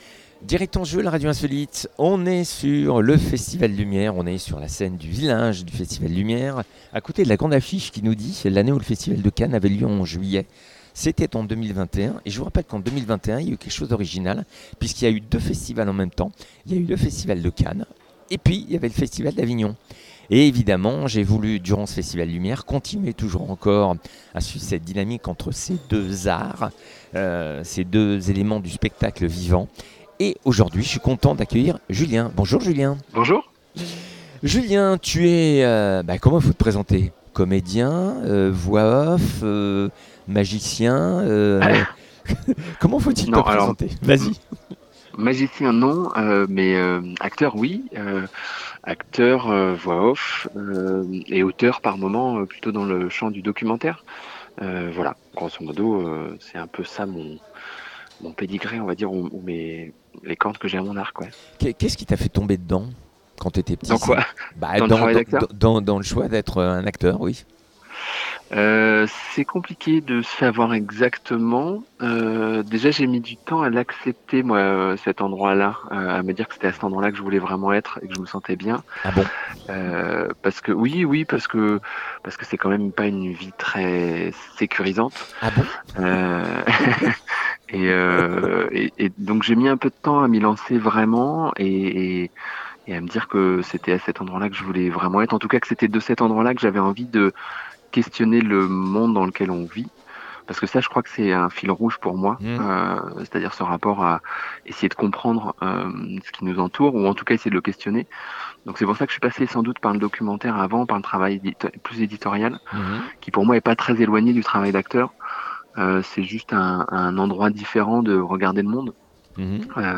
en partenariat avec Chacomdif en direct du Festival Lumière